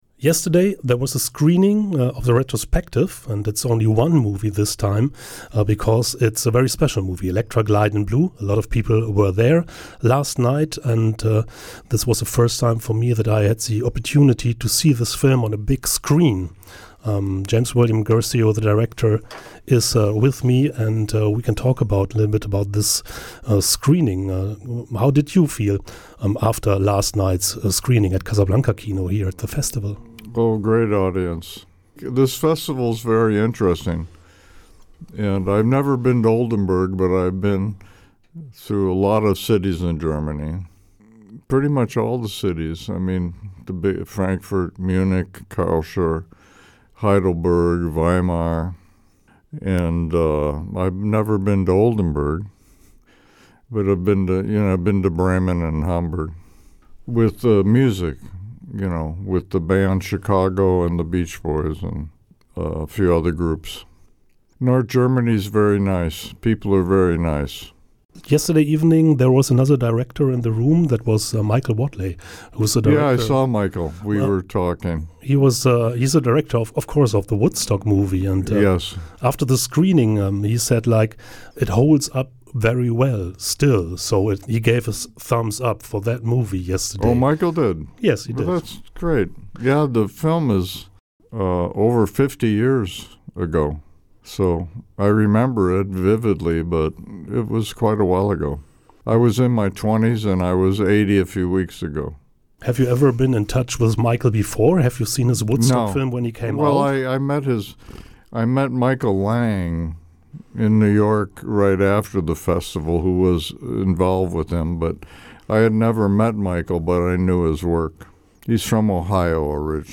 Radiointerview mit James William Guercio im Studio von Oldenburg Eins – 12.09.2025